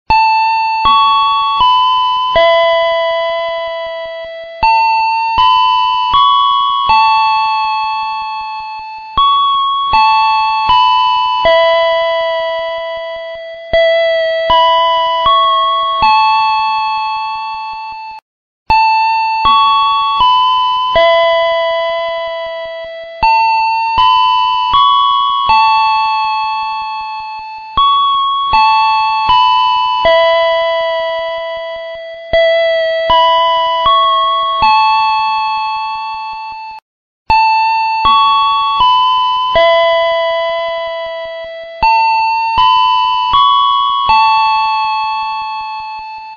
Suara Bel Stasiun Kereta Api
Keterangan: Download Efek Suara Ringtone Bel Stasiun, Suara Bel Stasiun Kereta Api Indonesia ini cocok banget buat kamu yang suka dengan suara khas dari stasiun kereta api.
suara-bel-stasiun-kereta-api-id-www_tiengdong_com.mp3